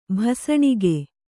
♪ bhasaṇige